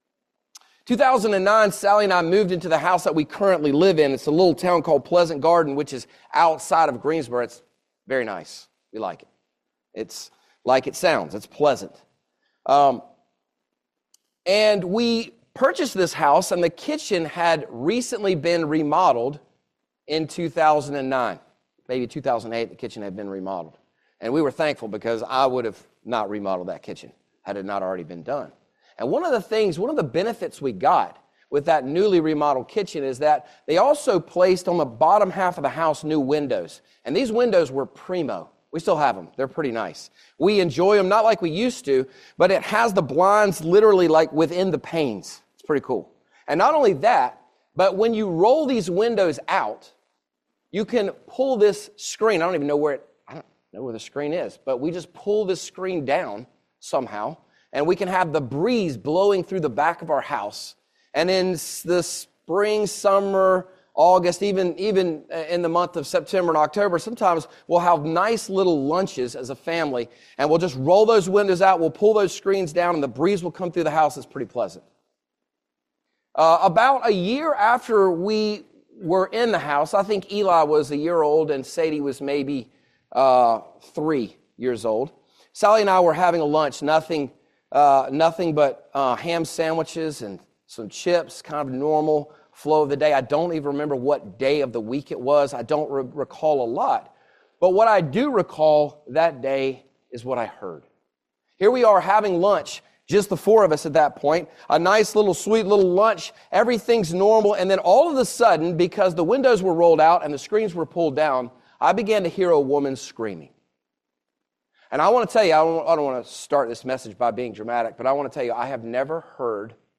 34-35 Service Type: Family Bible Hour Jesus repeats names and phrases He wants us to contemplate.